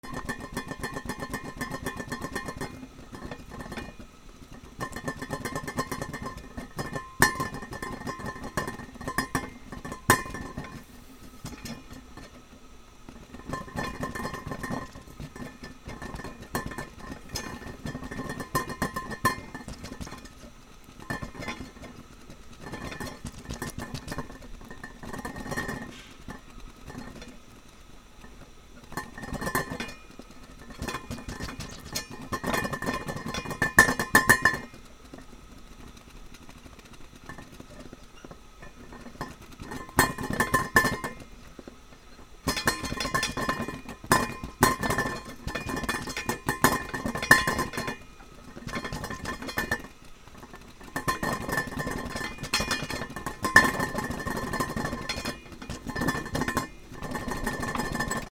なべ 沸騰
ふたが揺れる